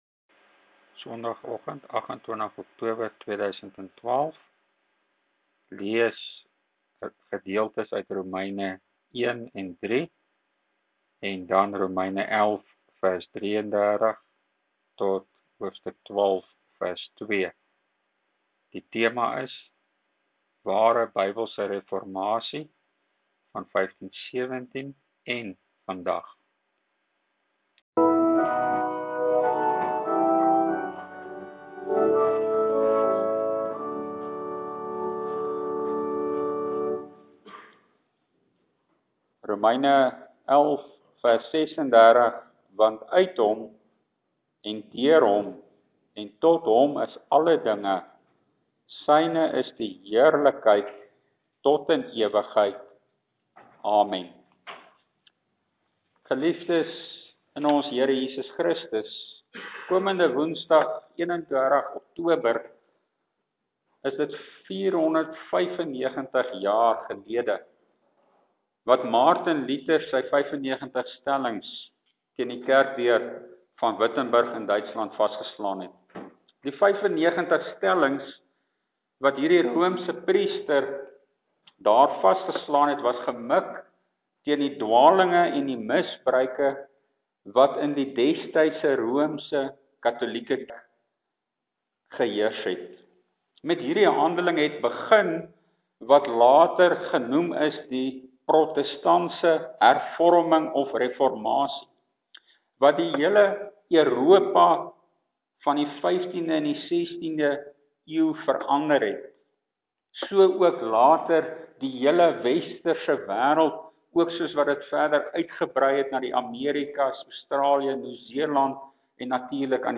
Ek plaas my preek van afgelope Sondagoggend hier, waarin ek ook die kwessie aanspreek dat die Reformasie (en ons geloof oor die algemeen) oor verlossing (regverdiging) en die gehoorsaamheid (heiligmaking/aanbidding) wat daaruit vloei gaan, nie net die een saak of die ander nie (NGB art.22-25; HK v/a 64). Jesus Christus is Verlosser én Koning.